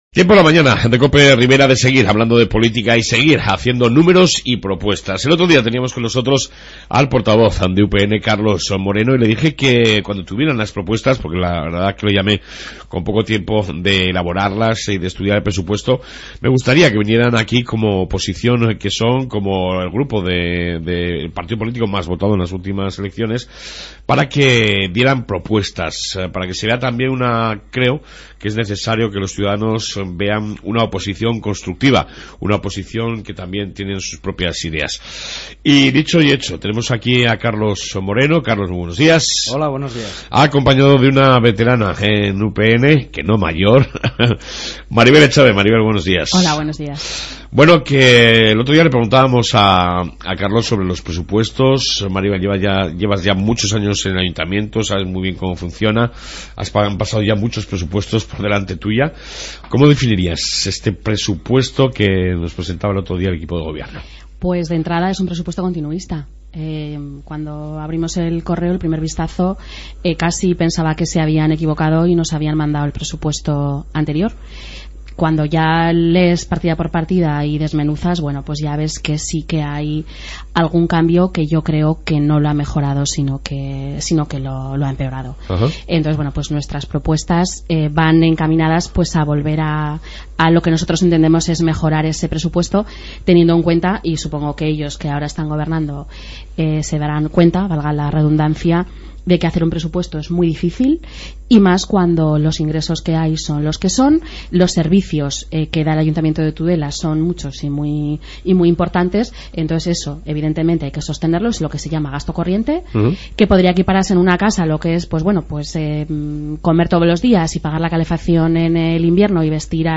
Entrevista con UPN sobre las propuestas a llevar al pleno de mañana